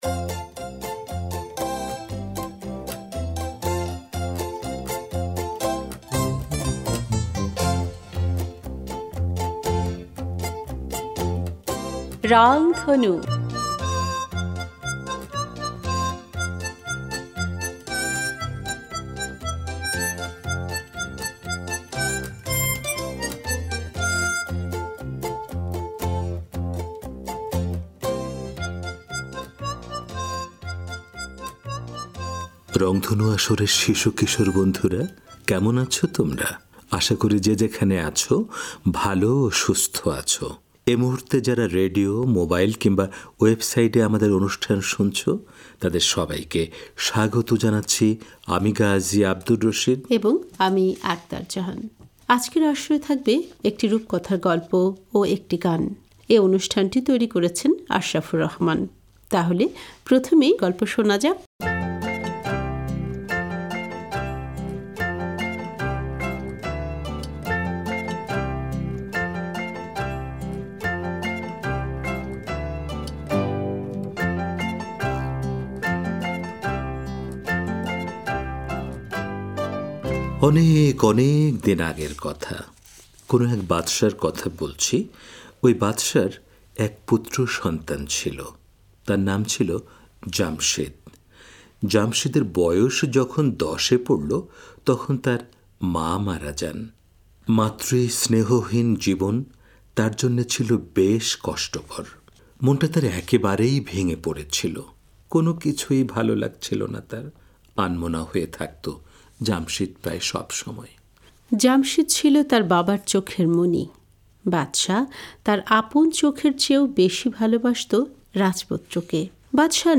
আজকের আসরে থাকবে একটি রূপকথার গল্প ও একটি গান।